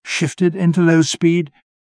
shifted-into-low.wav